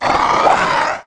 client / bin / pack / sound2 / sound / monster2 / spider_queen / attack_1.wav
attack_1.wav